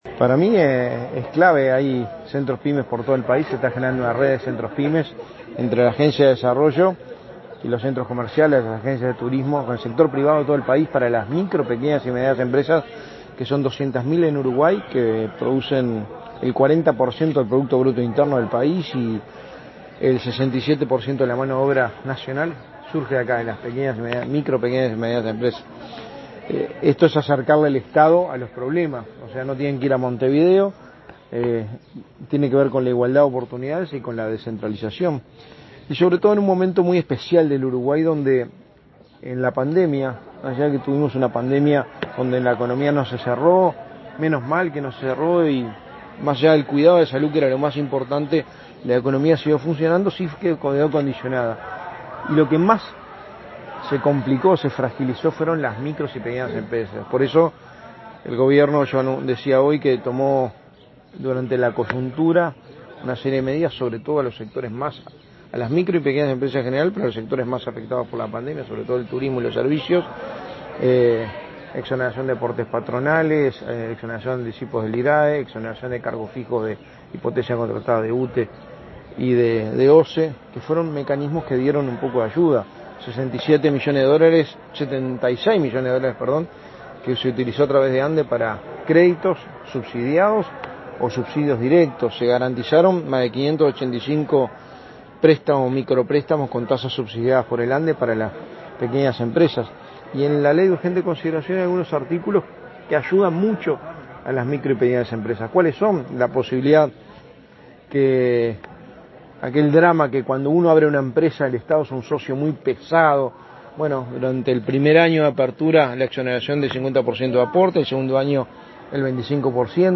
Declaraciones de prensa del secretario de Presidencia, Álvaro Delgado
El secretario de Presidencia, Álvaro Delgado, participó este viernes 10 de la inauguración de un centro Pyme en Colonia y, luego, dialogó con la